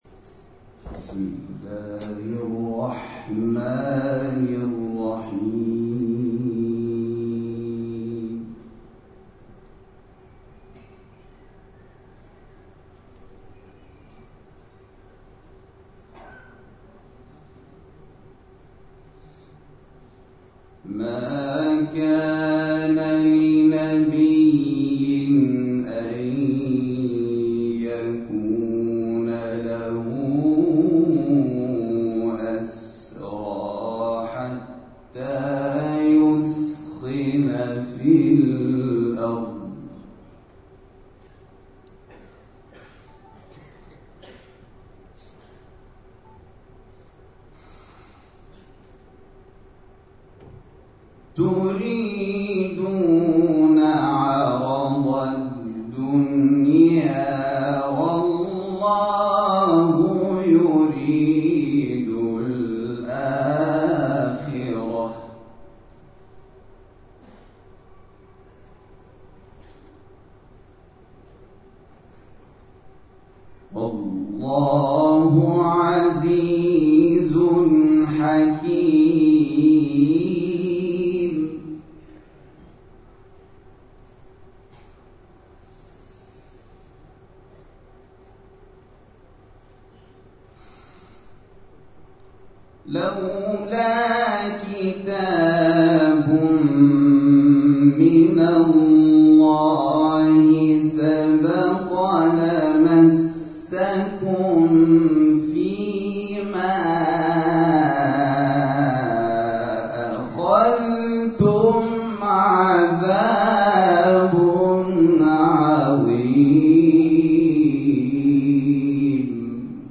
گروه مسابقات: دومین روز پنجاه و نهمین دوره مسابقات بین‌المللی قرآن کشور مالزی با تلاوت قاریانی از هشت کشور جهان پایان یافت.